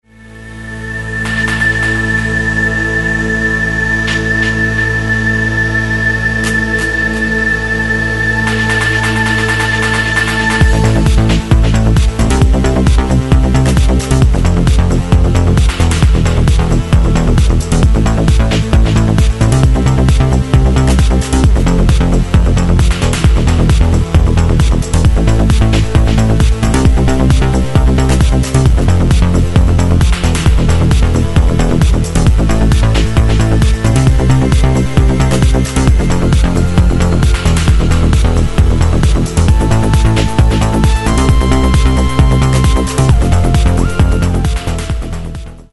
Styl: Electro, Techno